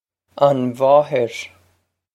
Pronunciation for how to say
on vaw-hir
This is an approximate phonetic pronunciation of the phrase.